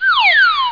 1 channel
00584_Sound_slide.mp3